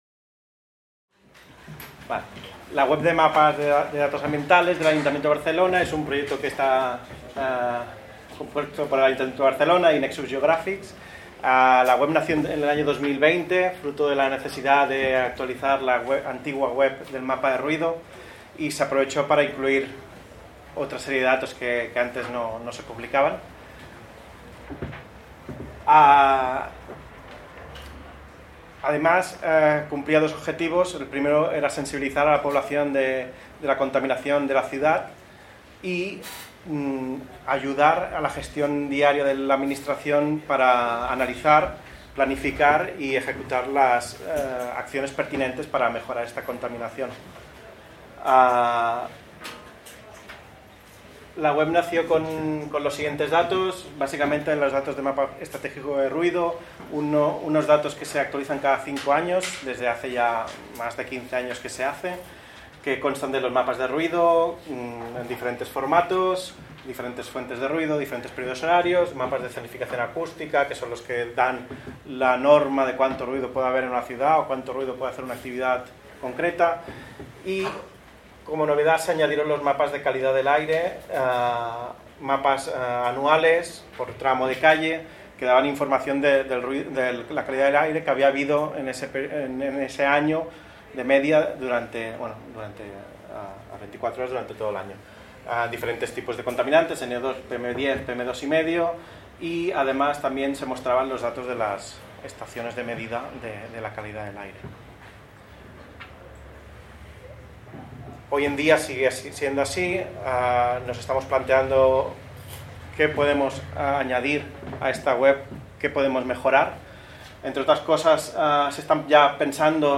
En el marc de les 18enes Jornades de SIG Lliure 2025, organitzades pel SIGTE de la Universitat de Girona